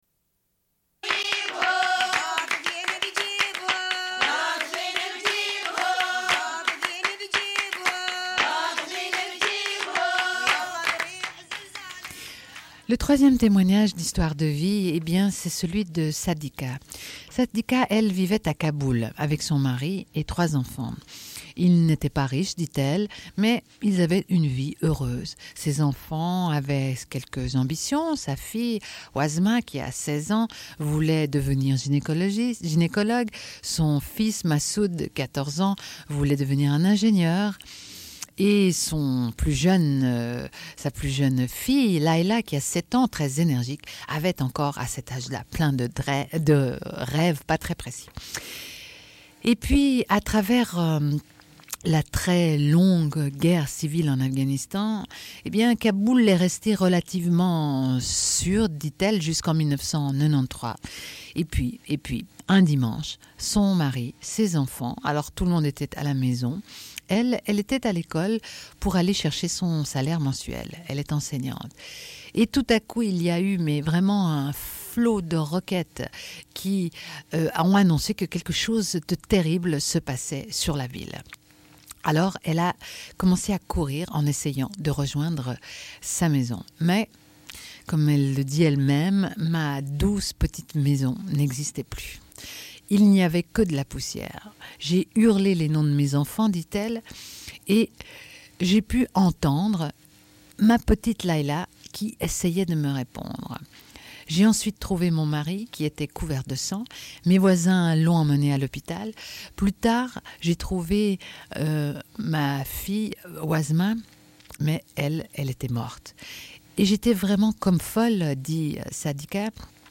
Une cassette audio, face B
Radio Enregistrement sonore